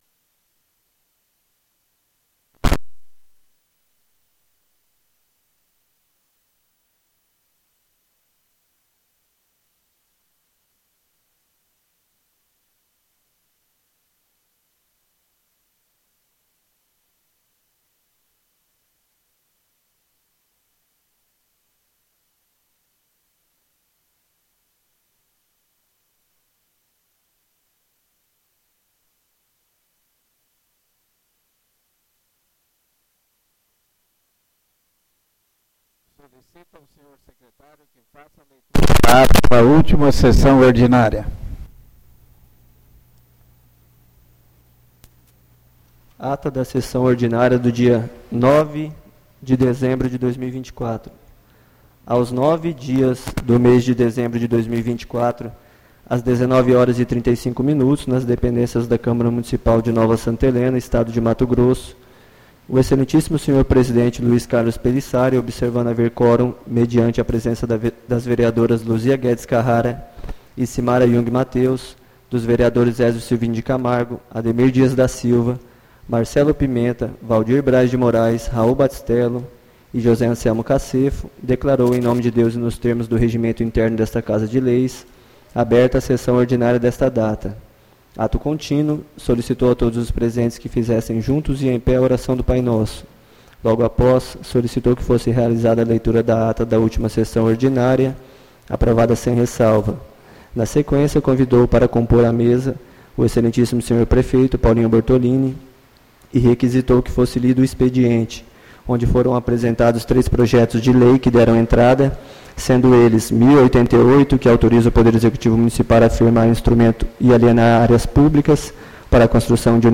ÁUDIO SESSÃO 16-12-24 — CÂMARA MUNICIPAL DE NOVA SANTA HELENA - MT